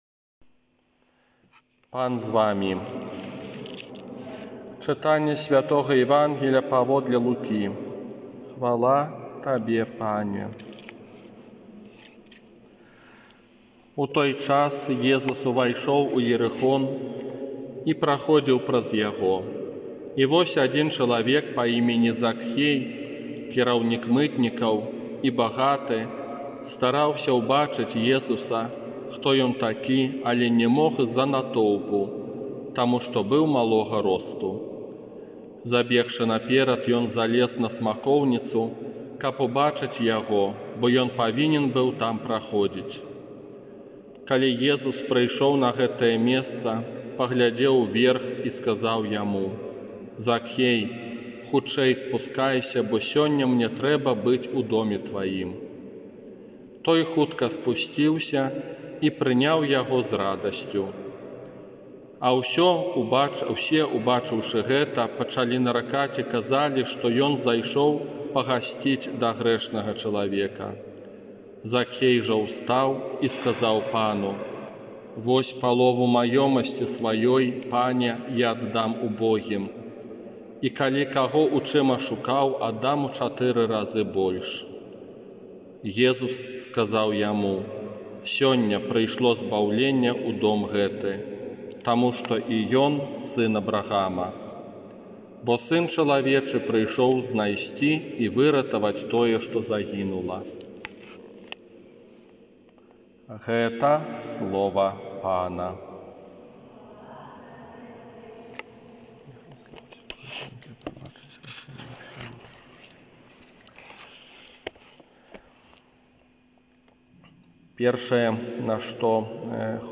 ОРША - ПАРАФІЯ СВЯТОГА ЯЗЭПА
Казанне на трыццаць першую звычайную нядзелю